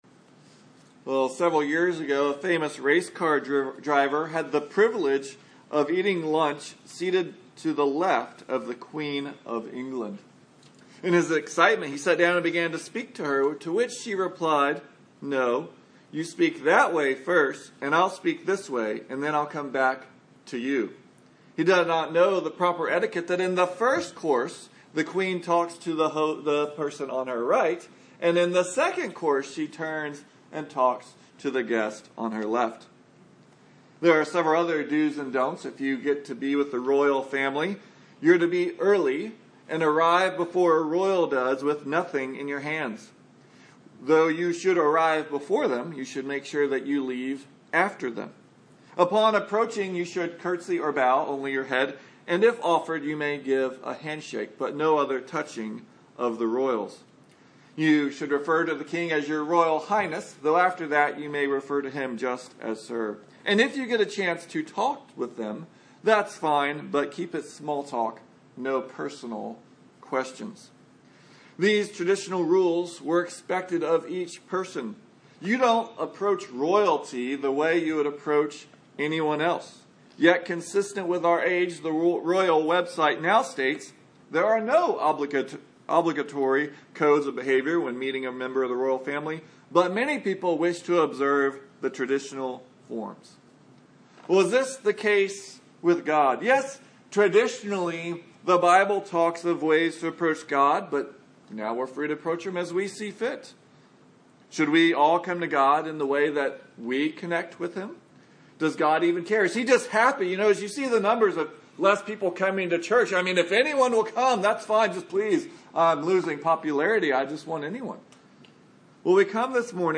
Numbers 3-4 Service Type: Sunday Morning The American way of religion is like a buffet